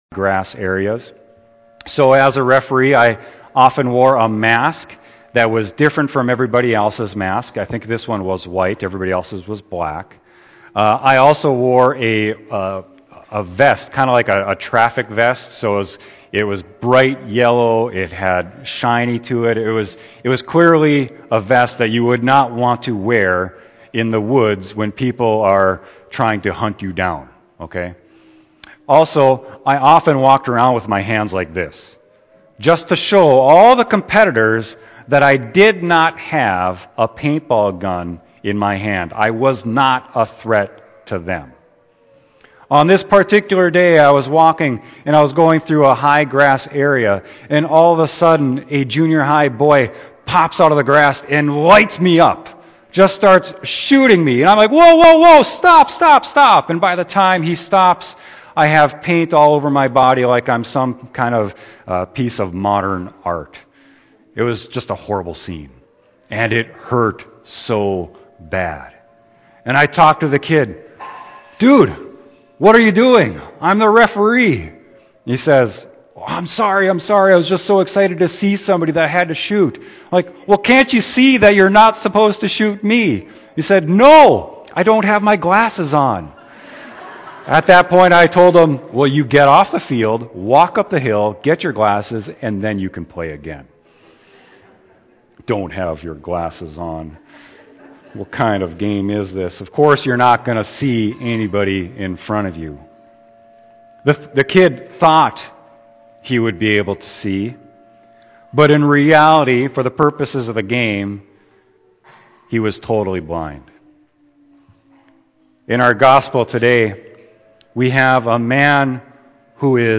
Sermon: John 9.1-41